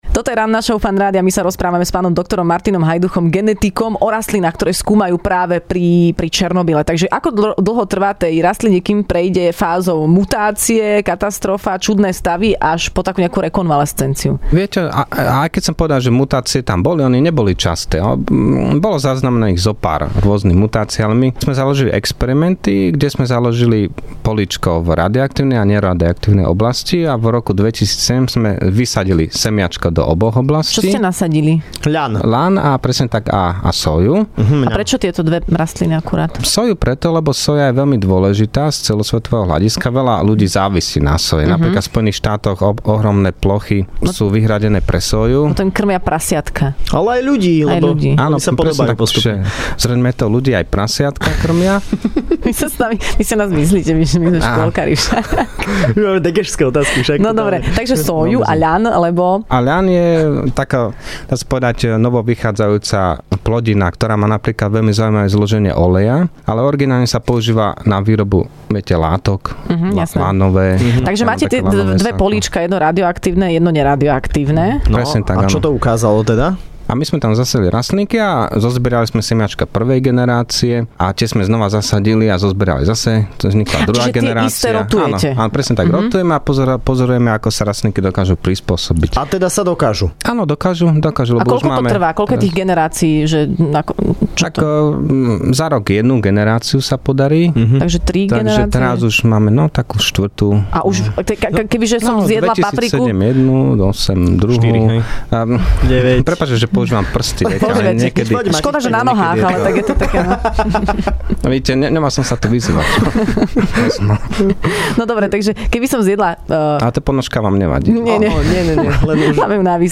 Hosťom v Rannej šou bol genetik